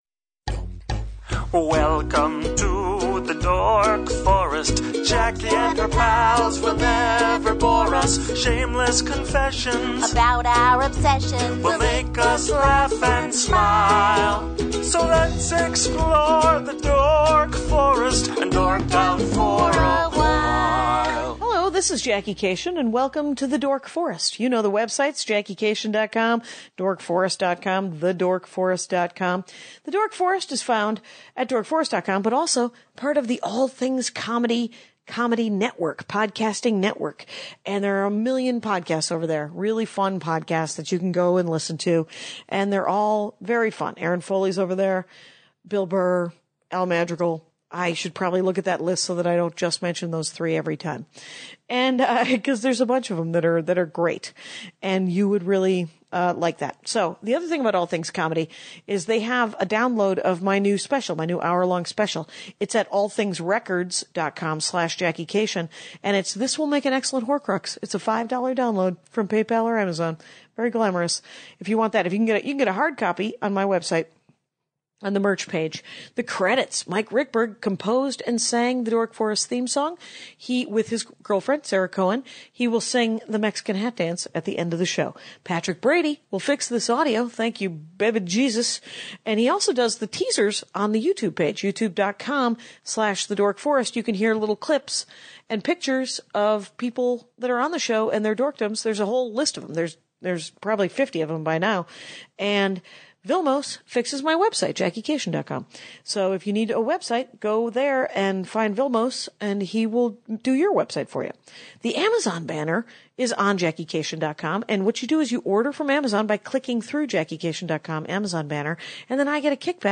She reads HER OWN AD.